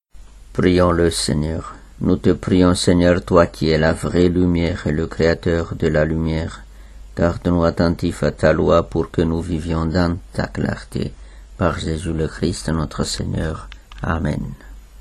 Jeudi 14°semaine du temps ordinaire [S2] - Chorale Paroissiale du Pôle Missionnaire de Fontainebleau
Antienne